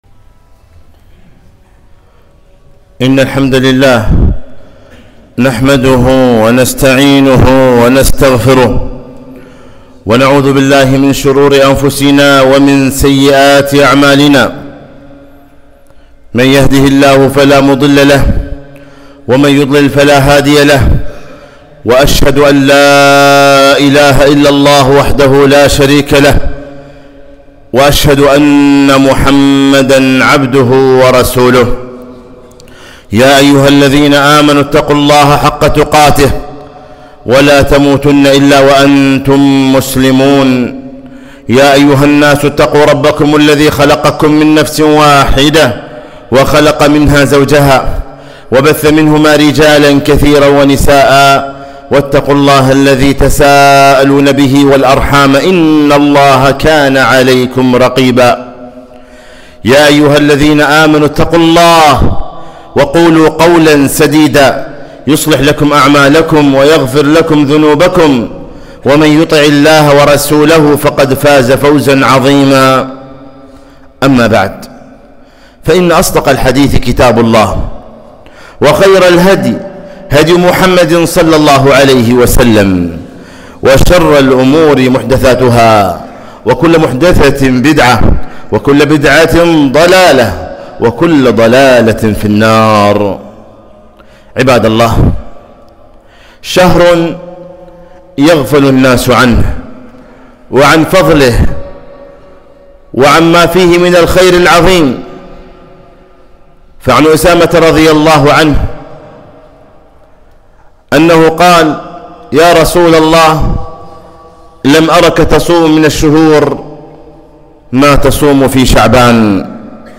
خطبة - شهر يغفل الناس عنه